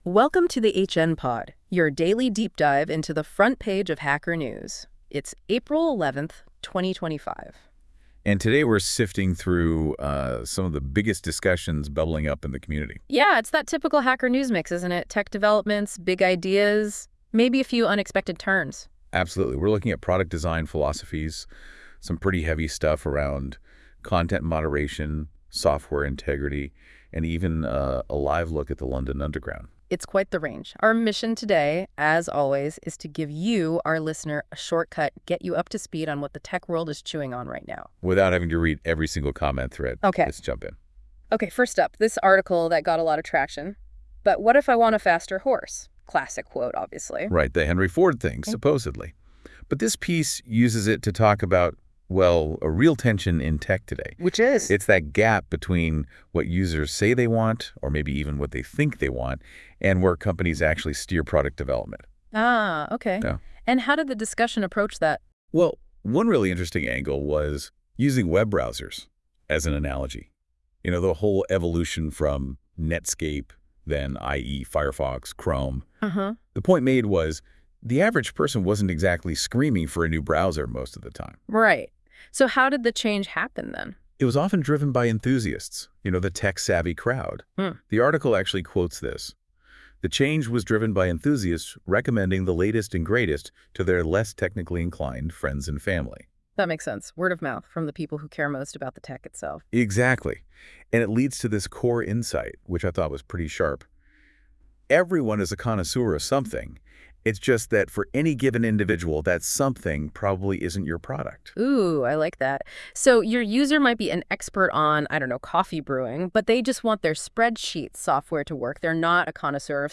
An AI-generated daily podcast that brings Hacker News stories to life through synthesized audio.